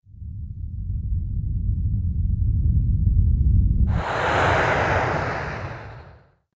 rocket_launch.ogg